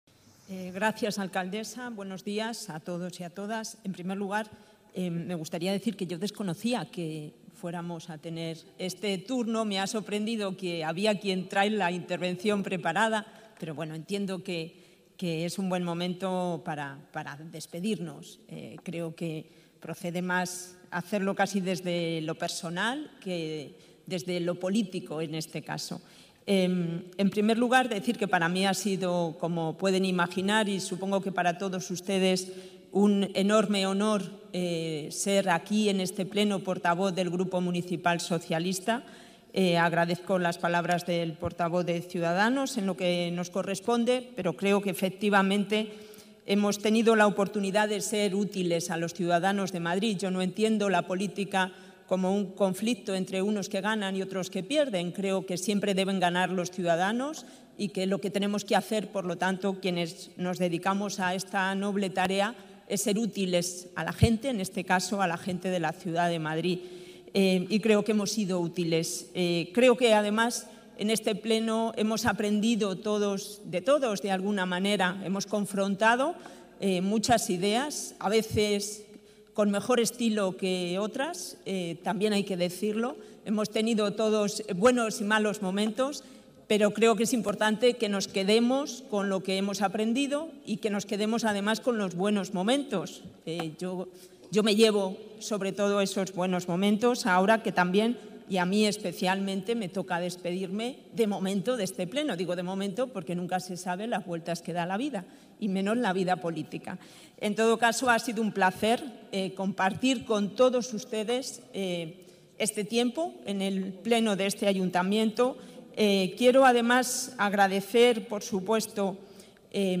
Hoy miércoles, 12 de junio, el Palacio de Cibeles ha acogido la celebración del último pleno del mandato 2015-2019, en el que se han aprobado las actas de las últimas sesiones celebradas por cada una de las Comisiones Permanentes Ordinarias.
Purificación Causapié. Grupo municipal socialista
UltimoPlenoCorporacionPCausapie-12-06.mp3